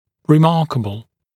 [rɪ’mɑːkəbl][ри’ма:кэбл]заслуживающий внимания, выдающийся, замечательный